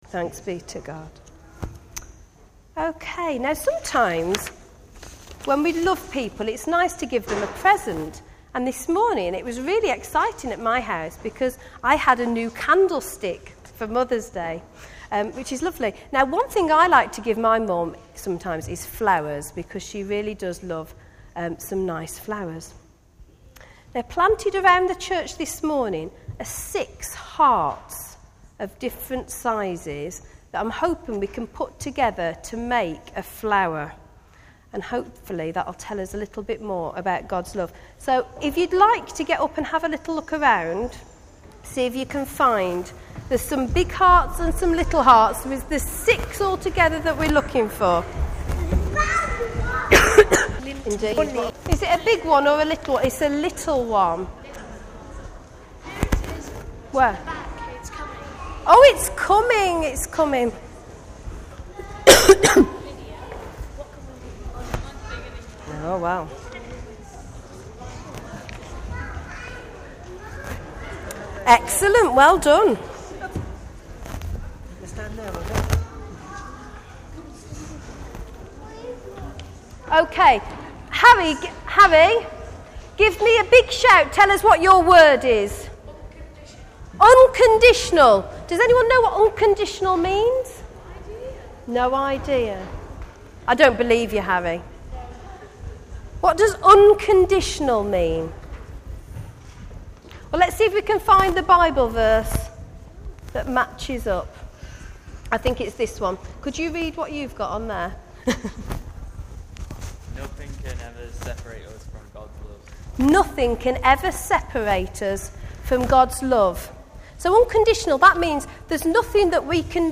St John's service